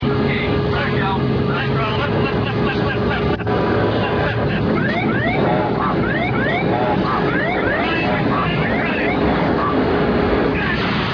COCKPIT VOICE RECORDING